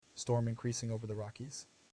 vox-cloned-data
Text-to-Speech